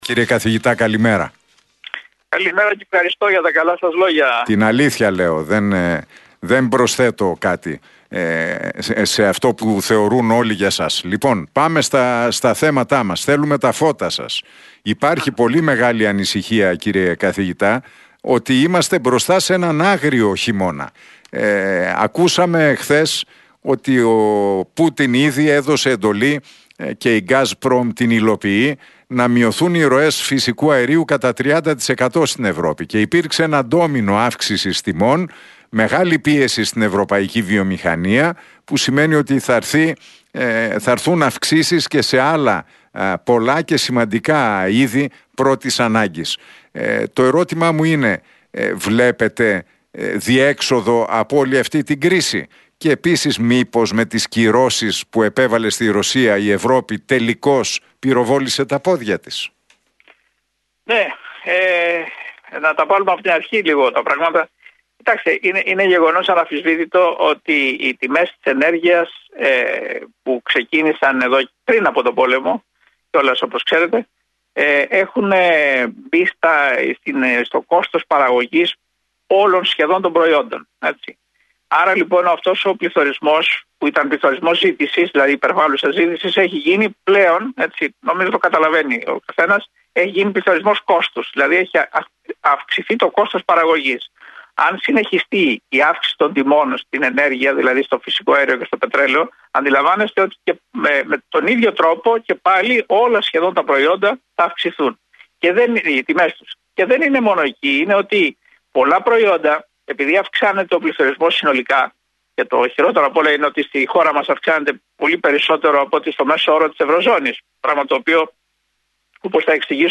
μιλώντας στον Realfm 97,8